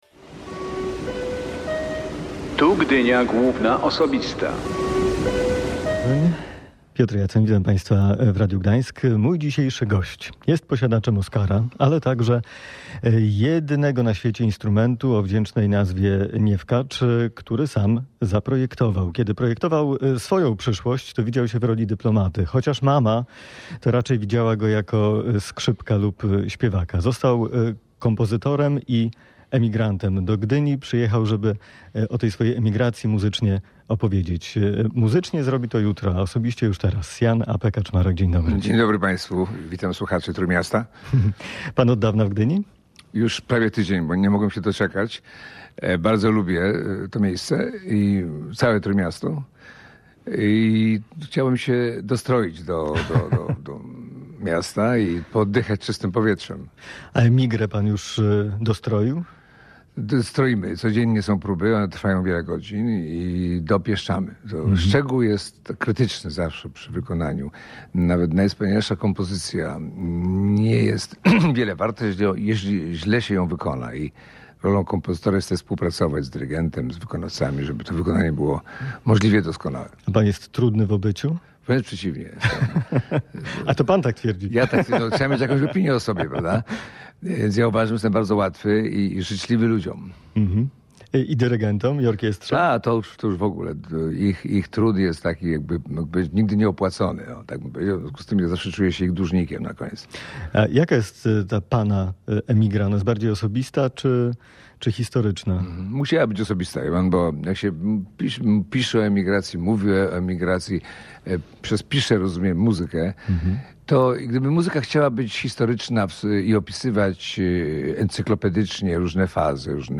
W audycji Gdynia Główna Osobista opowiadał, że poszczególne fazy – emigracja była dla niego bardzo szczęśliwym czasem, ale zapłacił też za nią potężną cenę.